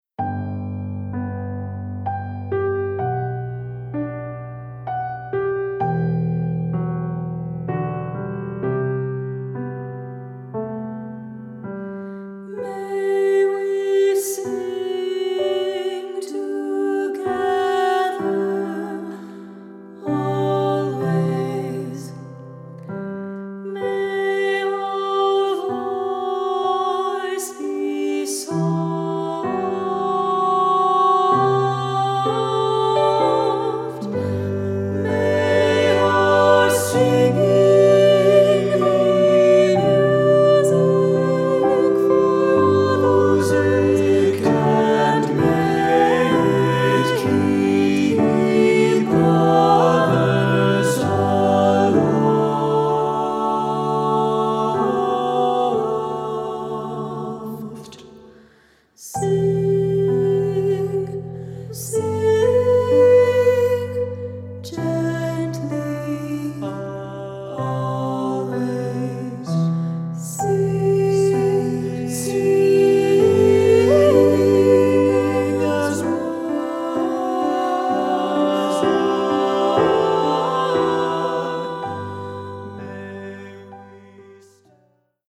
Choral Concert/General Women's Chorus
SSA